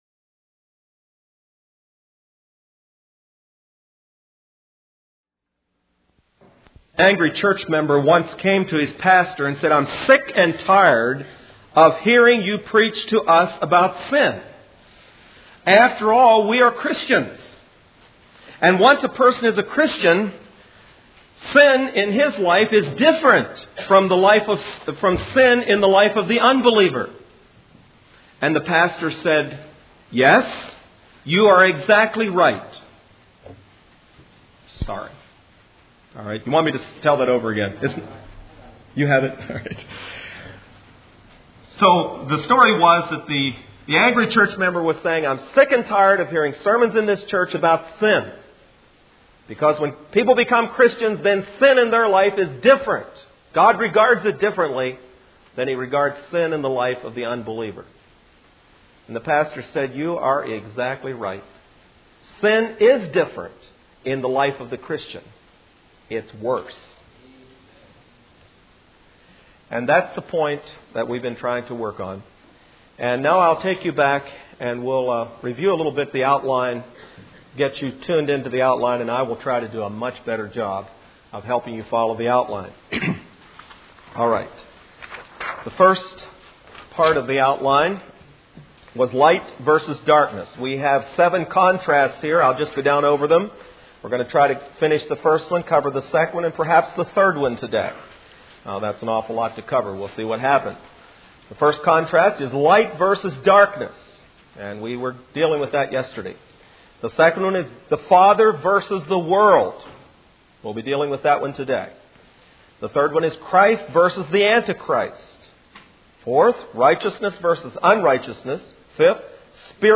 Facing The Facts Service Type: Midweek Meeting Speaker